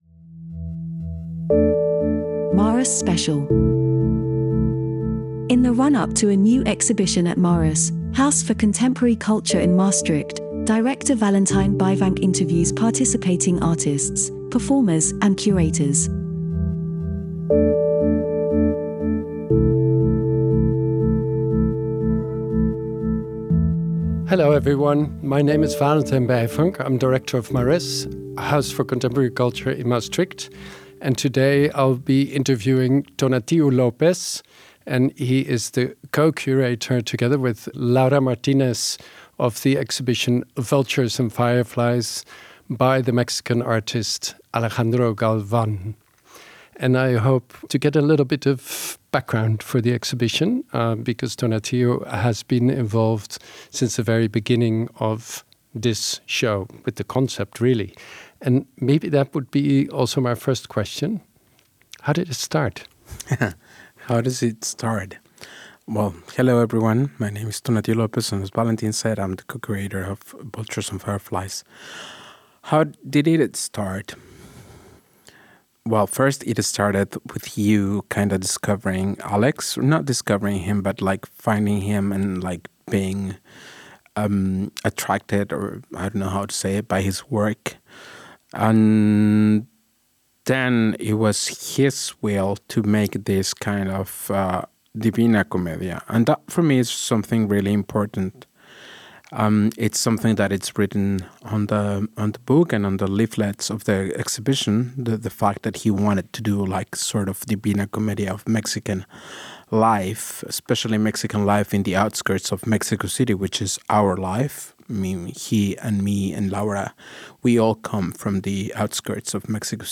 interviews participating artists, performers and curators.